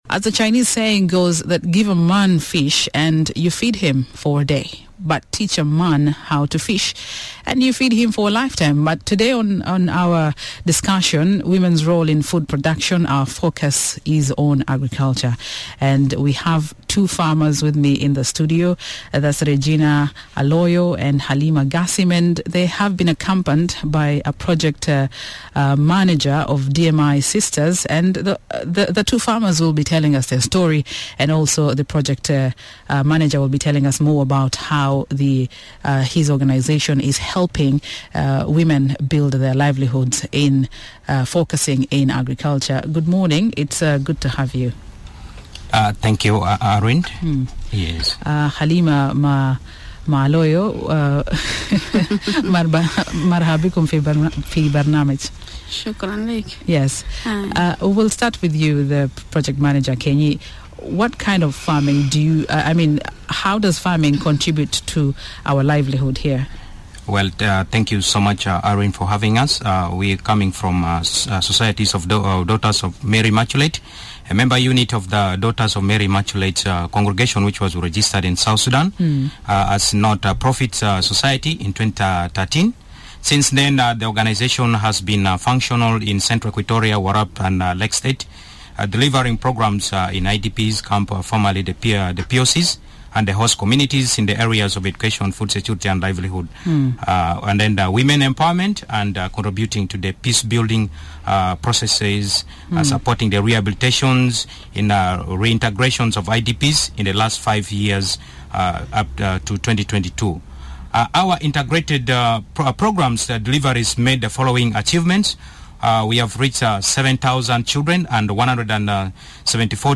Fighting, flooding, and donor fatigue could be characterizing the current lifestyle of many South Sudanese, but some women have vowed to come out of the situation and be self-sustaining. Miraya breakfast this morning featured two female farmers who are being assisted by a non-governmental organization in contributing to the nation’s food basket.